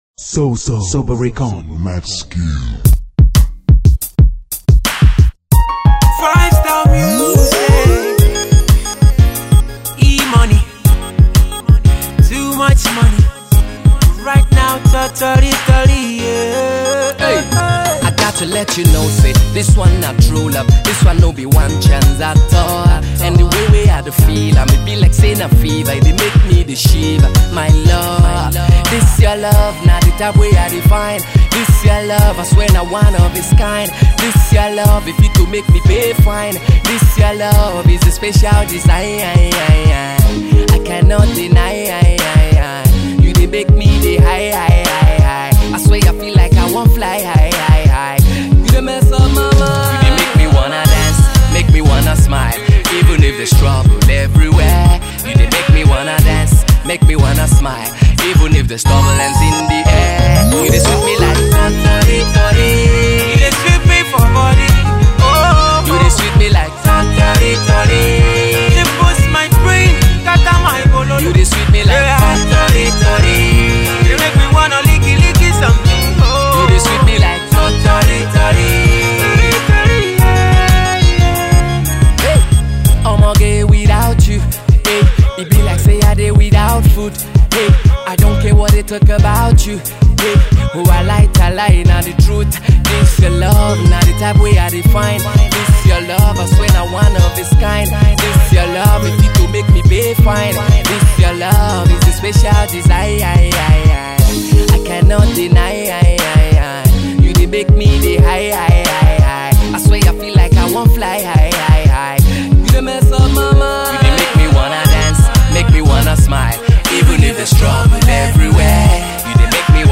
a more laid back love song